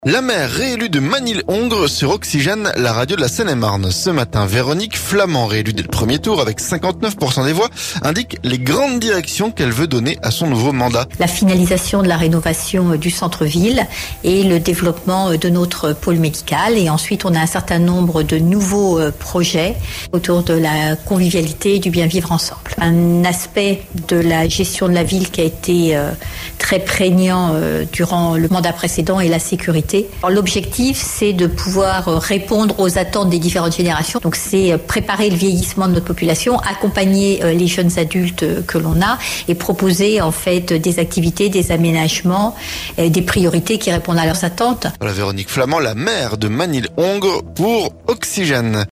La maire réélue de Magny-le-hongre sur Oxygène, la radio de la Seine-et-Marne ce mercredi. Véronique Flament, réélue dès le 1er tour avec 59% des voix, indique les grandes directions qu'elle veut donner à son nouveau mandat.